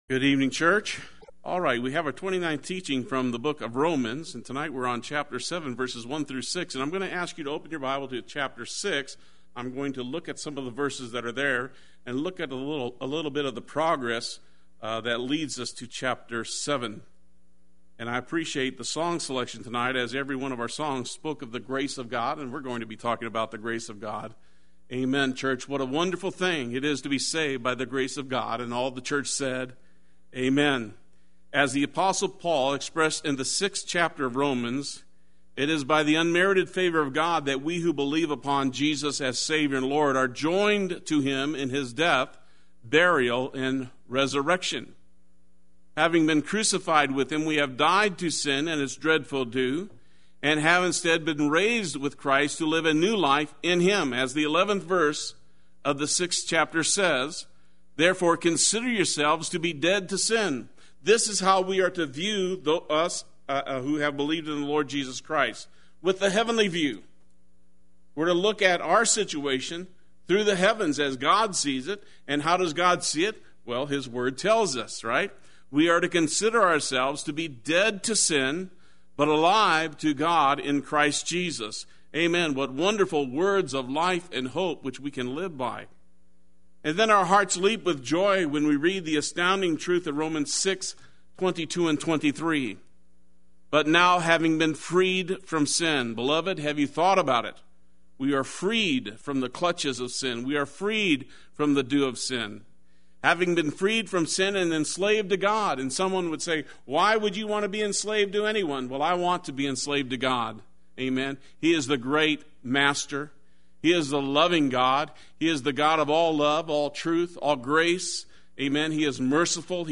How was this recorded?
Joined…to Him…for God Wednesday Worship